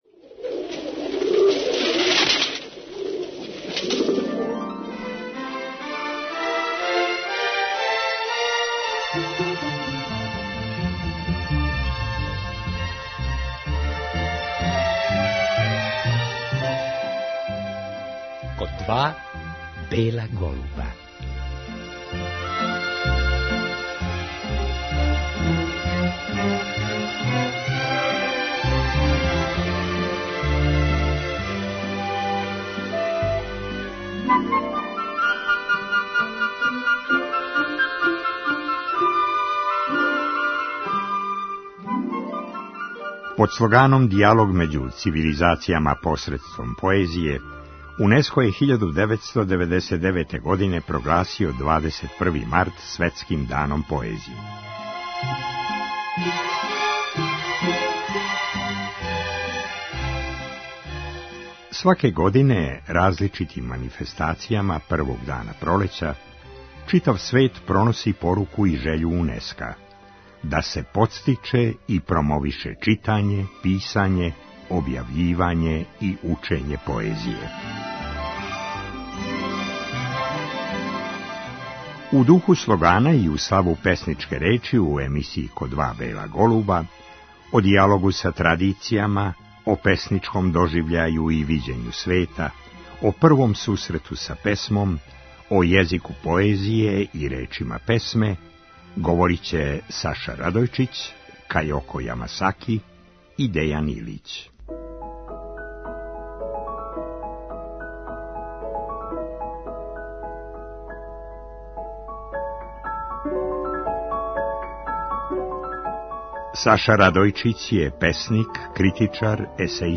Поводом Светског дана поезије, о песништву говоре и стихове читају савремени српски песници.